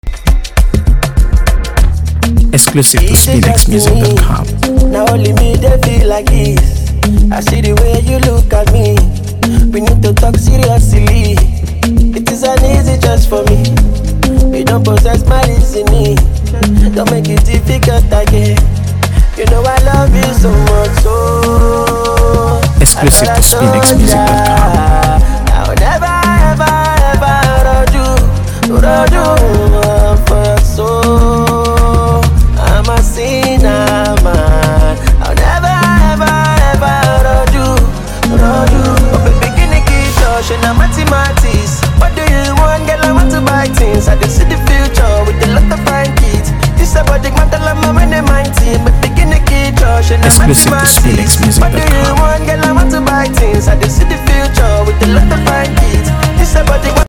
AfroBeats | AfroBeats songs
With a charming melody and uplifting vibe